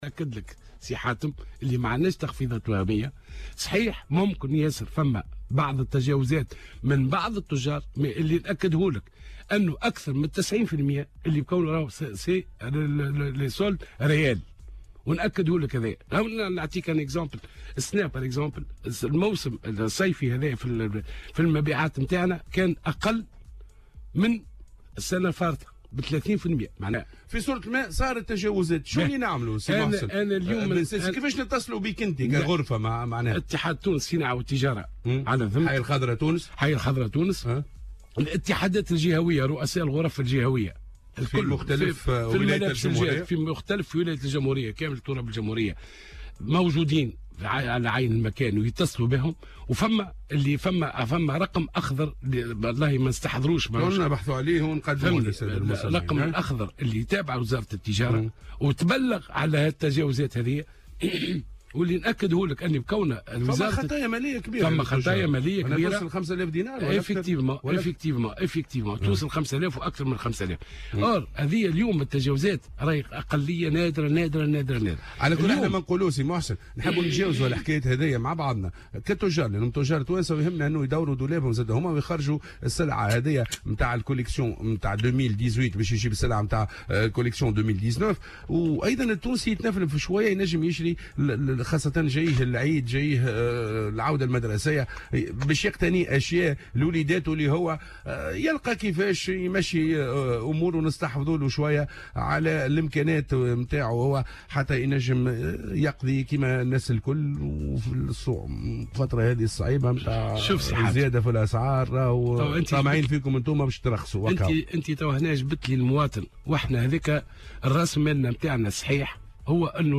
وقال في مداخلة له اليوم في برنامج "صباح الورد" إن أكثر من 90 بالمائة من التخفيضات هي حقيقية، مشيرا في السياق نفسه إلى أنه تم تسجيل تراجع في المبيعات خلال هذه الصائفة مقارنة بالصائفة الماضية بـ30 بالمائة.